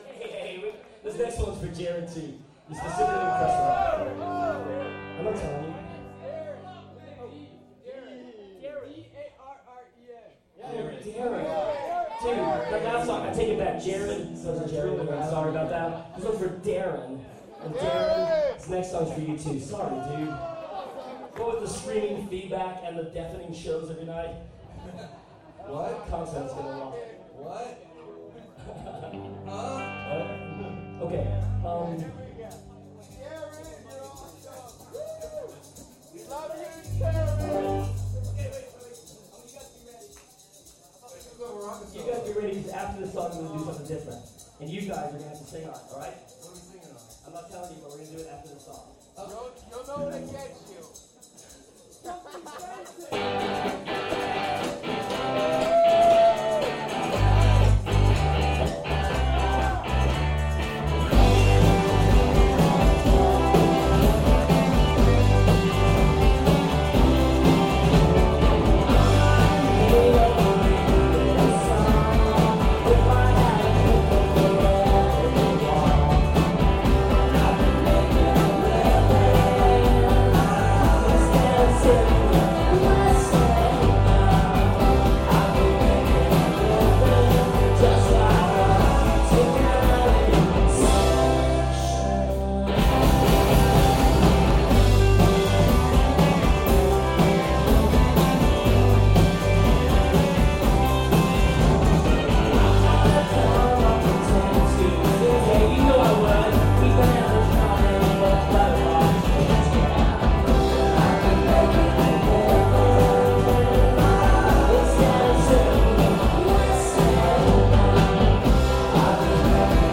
64kbit Mono MP3s